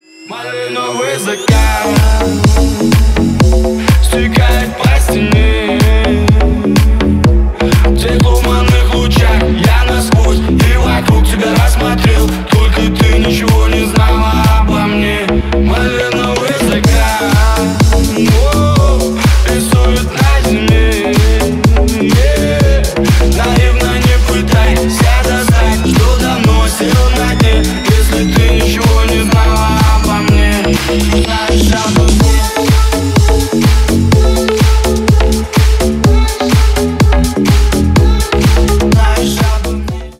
• Качество: 320 kbps, Stereo
Рэп и Хип Хоп
Танцевальные